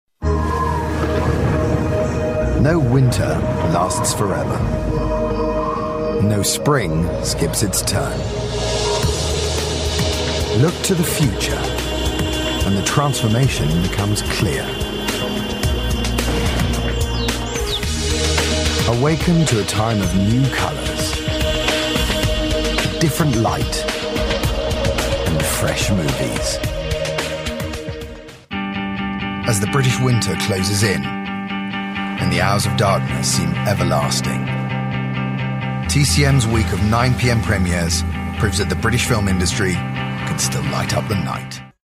30/40's Neutral/RP, Reassuring/Versatile/Gravitas